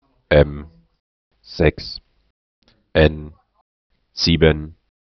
Test Klaviermusik (öffnet im neuen Fenster)